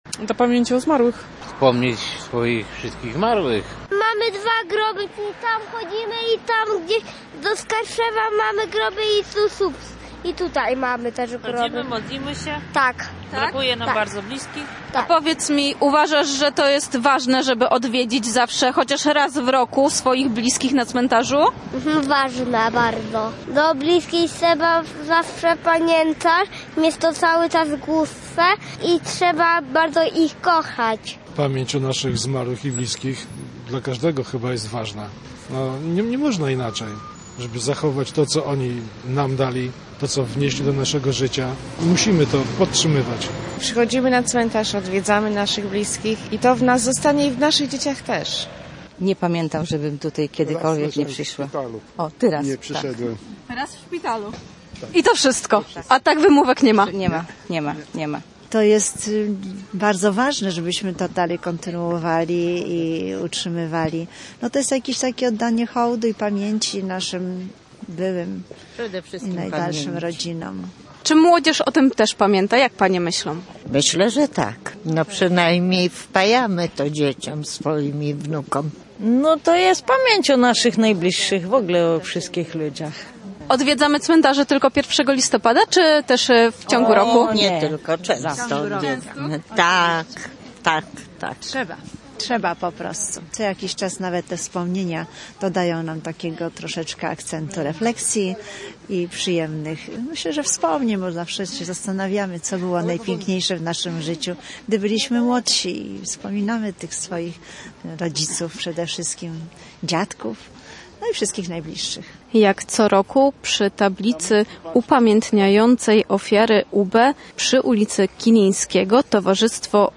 Nasza reporterka zapytała słupszczan, dlaczego warto pielęgnować tę tradycję.